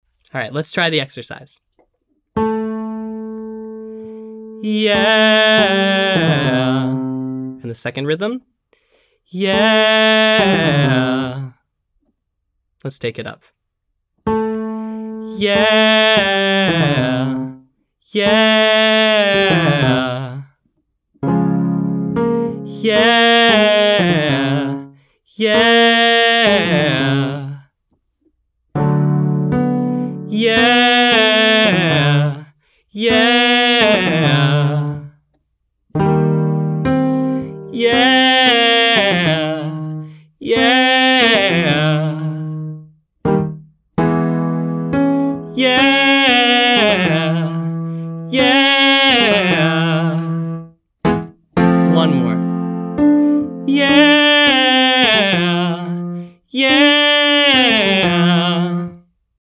From: R&B Daily Practice for Low Voices
Our first exercise starts at the 6th scale degree, and descends.
Exercise: pentatonic riff 6-5, 321, 6–5321